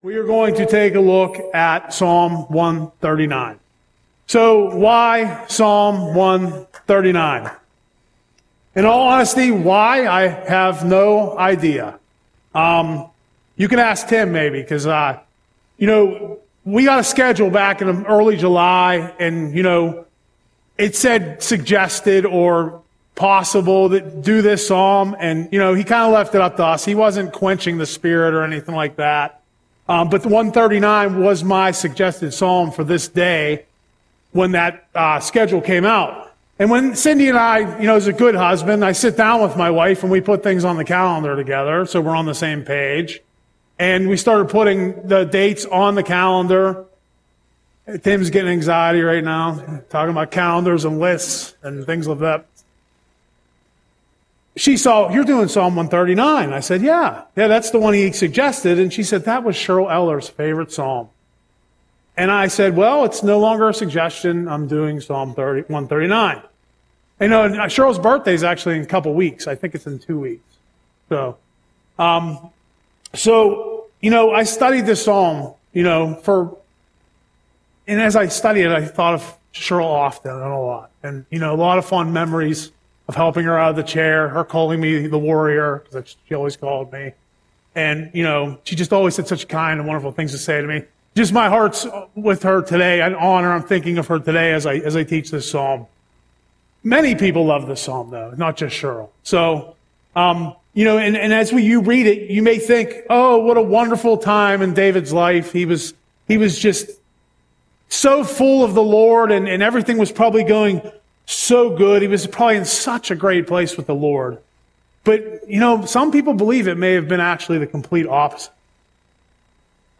Audio Sermon - August 20, 2025